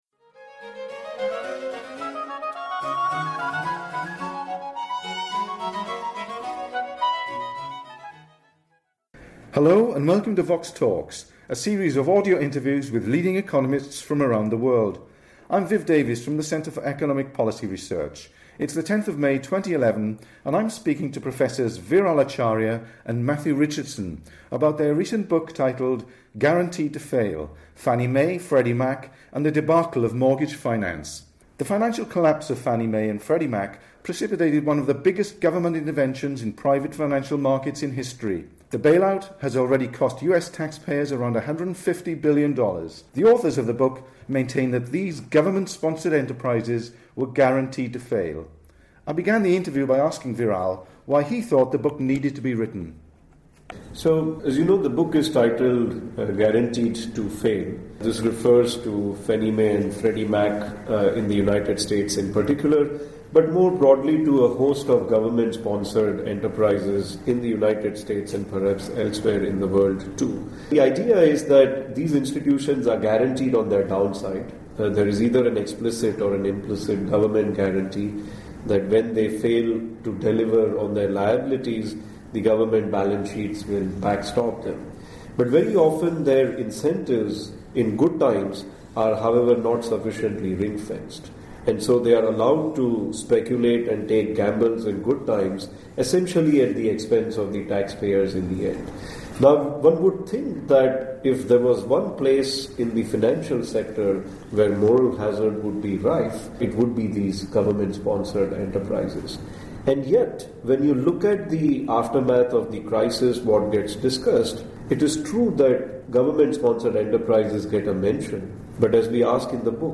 The financial collapse of these government sponsored enterprises led to a government intervention that has already cost US taxpayers around $150 billion. The authors discuss how these institutions collapsed, why housing finance in the US is broken and what now needs to be done to reform the system. The interview was recorded in London in May 2011.